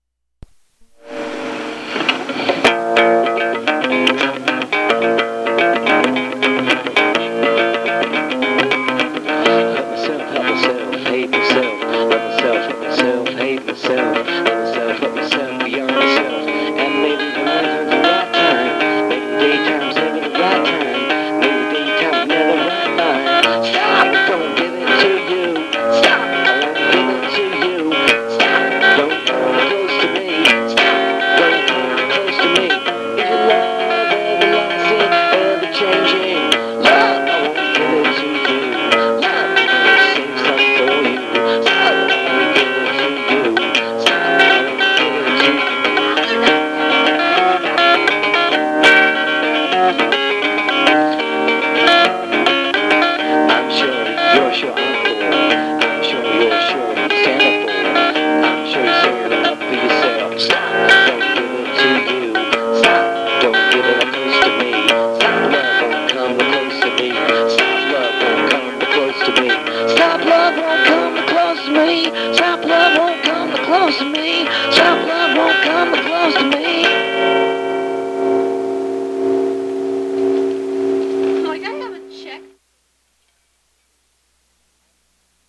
(apologies for poor sound quality)